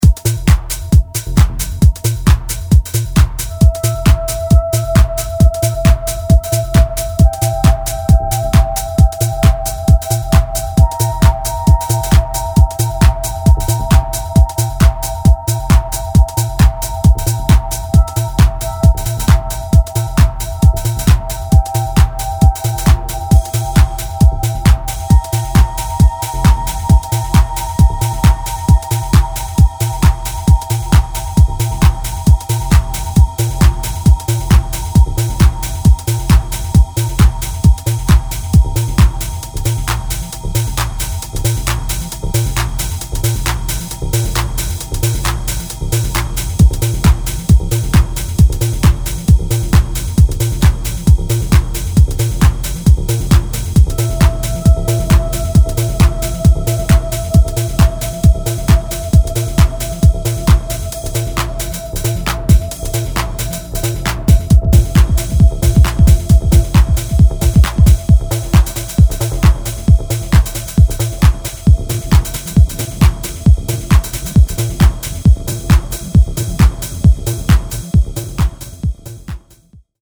Trippy minimalistic Techno